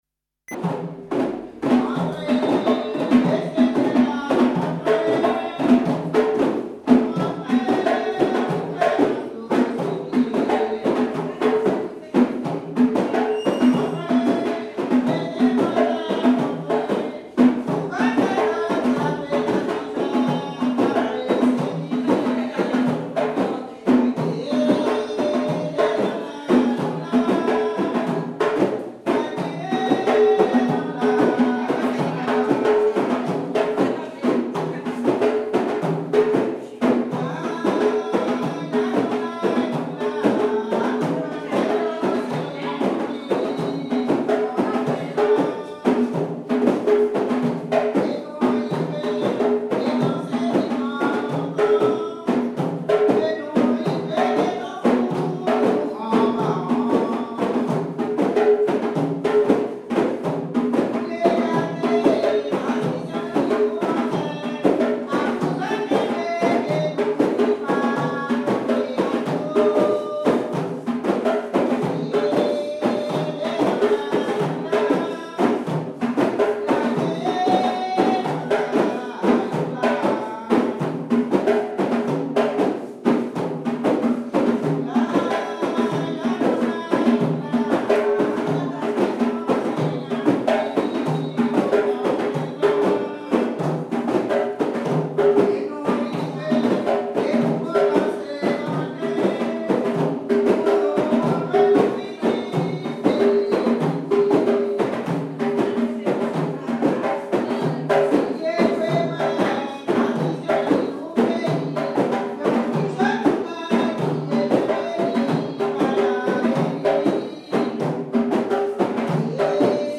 danse : grajé (créole)
Pièce musicale inédite